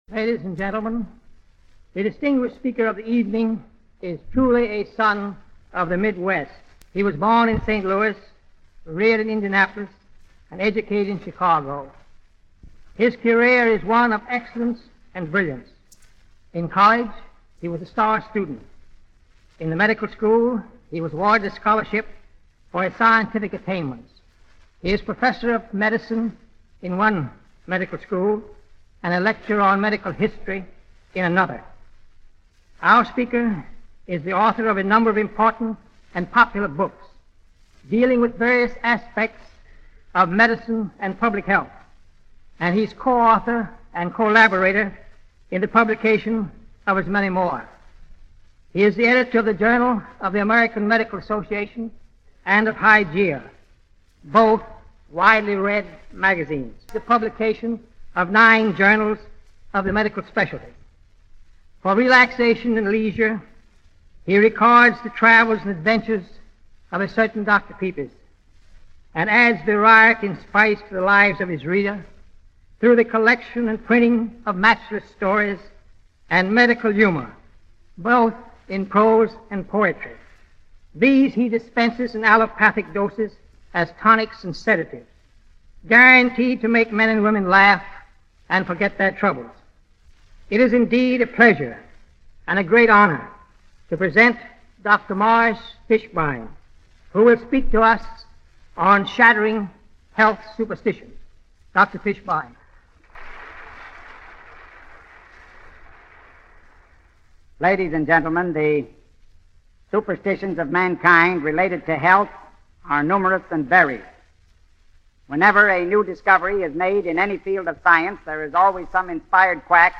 Dr. Morris Fishbein lecturing on the wide ranging medical myths that ran amok in America in the first half of the twentieth century.